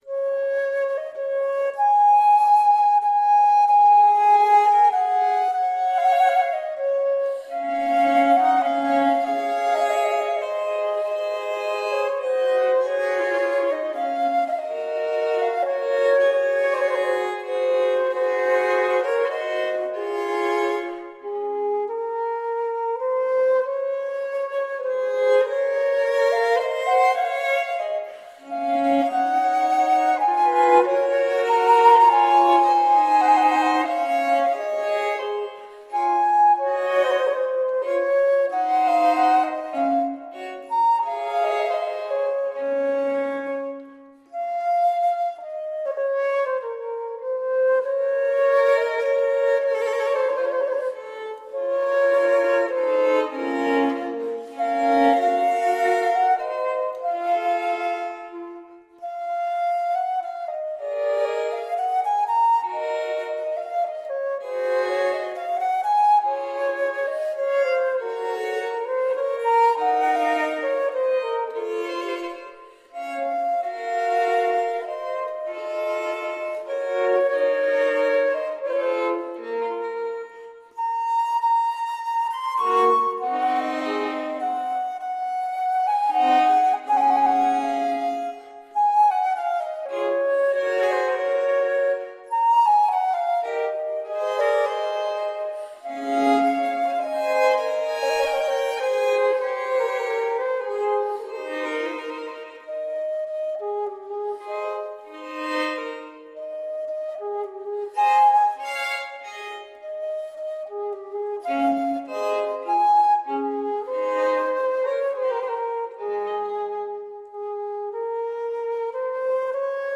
Exquisite chamber music.